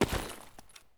255081e1ee Divergent / mods / Soundscape Overhaul / gamedata / sounds / material / human / step / gravel1.ogg 36 KiB (Stored with Git LFS) Raw History
gravel1.ogg